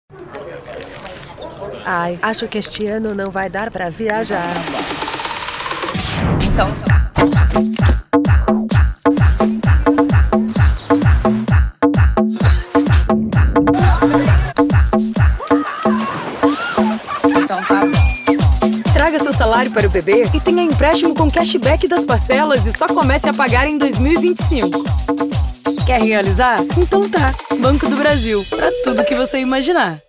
Voz com um leve “rouquinho” pra deixar seu anuncio com um toque único, trazendo mais sofisticação e visibilidade no mercado publicitário.